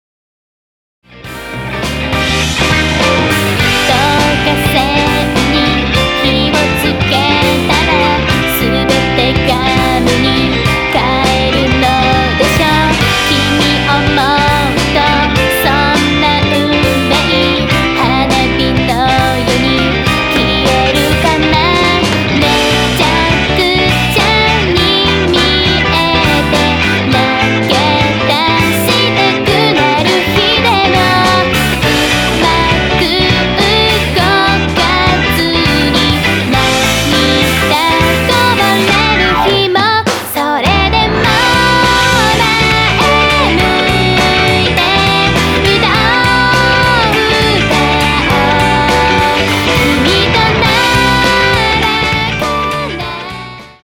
クロスフェードデモ
最高の東方フルボーカルアレンジアルバムが完成しました！
我が儘に追及したスーパーハイテンションアルバムとなっております！